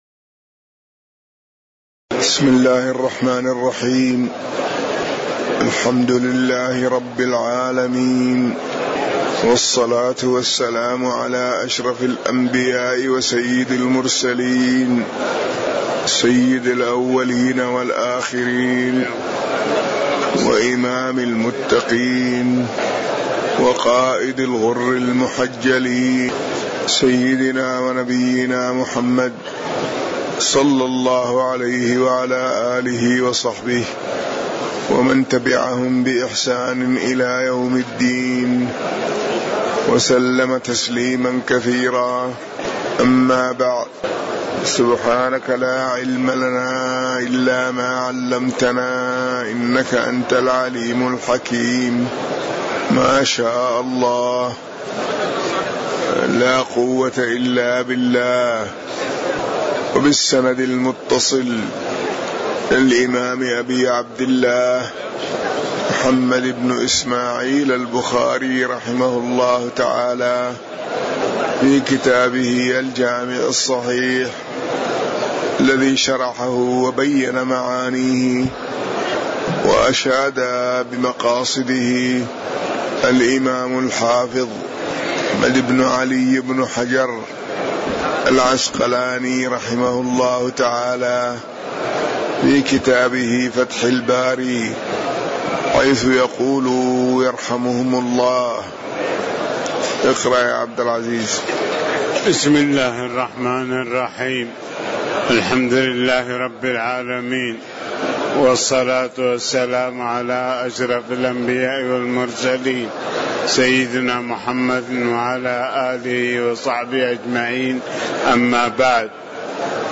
تاريخ النشر ١٠ رمضان ١٤٤٠ هـ المكان: المسجد النبوي الشيخ